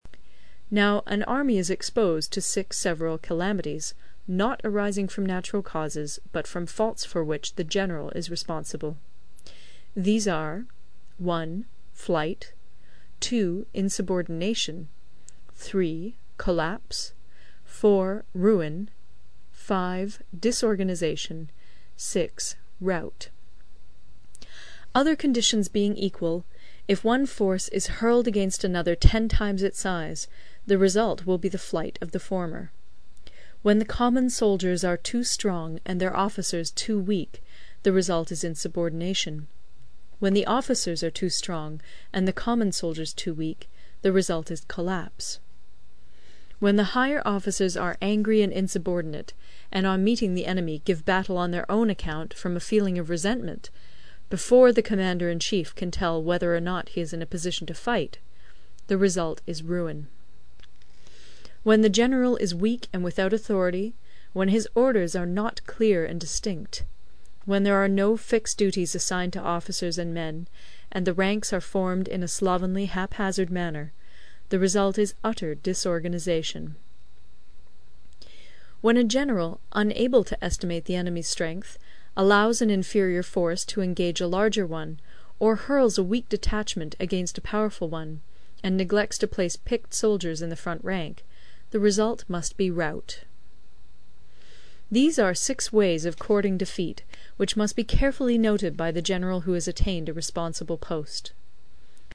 有声读物《孙子兵法》第58期:第十章 地形(2) 听力文件下载—在线英语听力室